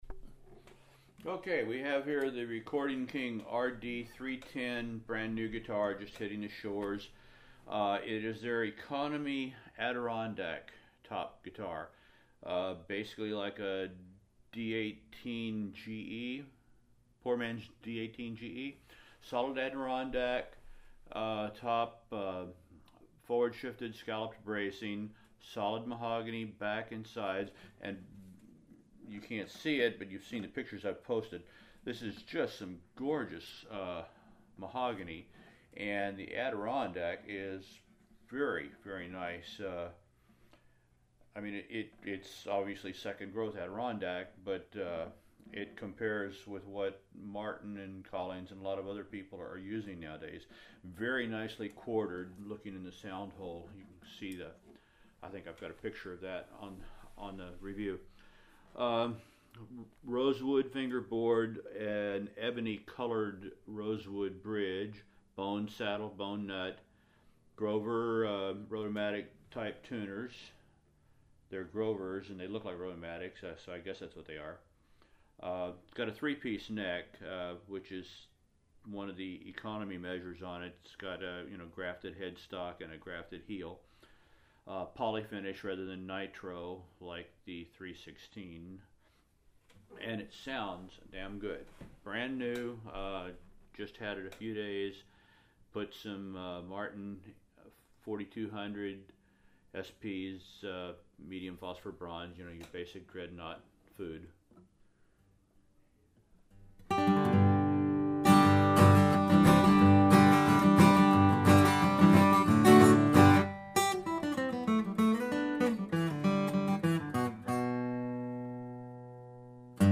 Звучит очень сбалансировано, IMO ... очень приятно.
Кстати, хороший пример голоса тоже. :)